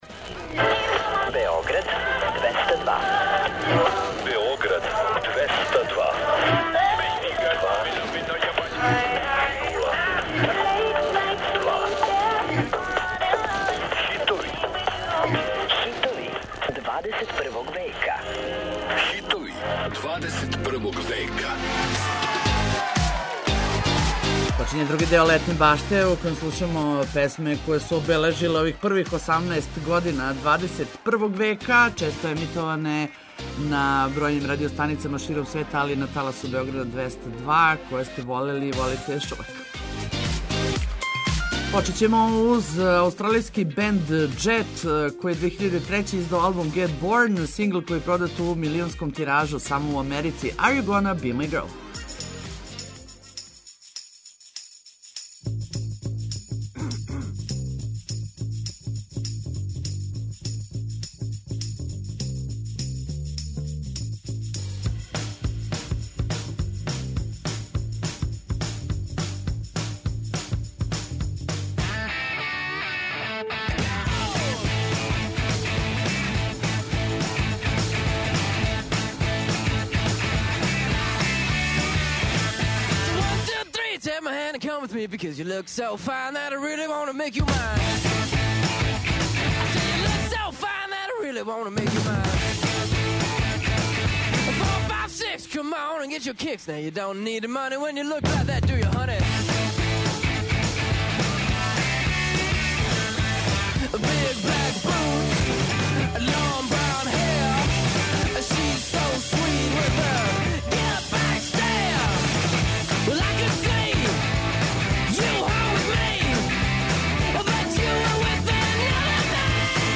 Очекују вас највећи хитови 21. века!